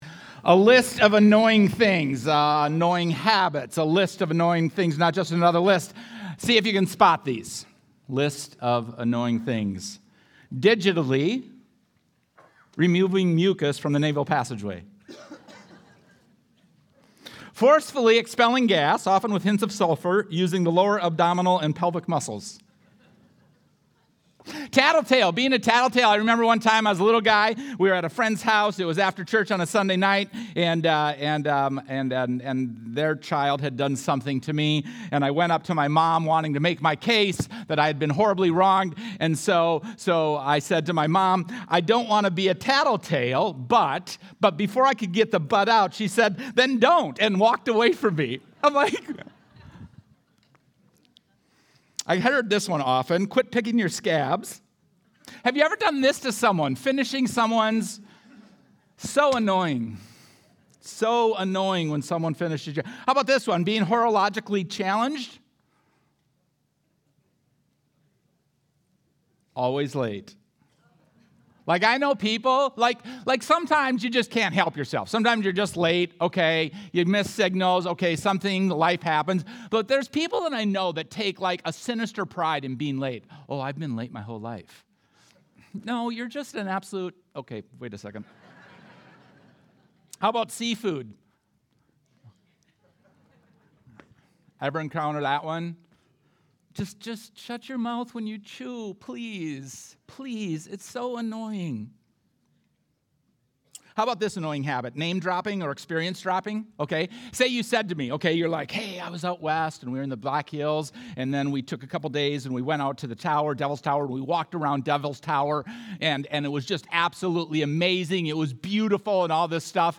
Sunday Sermon: 7-6-25